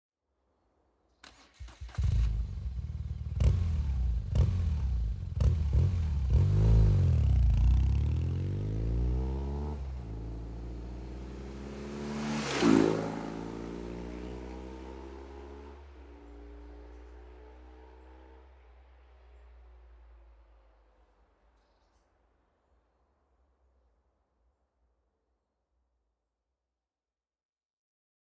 BMW GS SOUNDCHECK
Dynamic Mode - A tamed Mr. Hyde sound.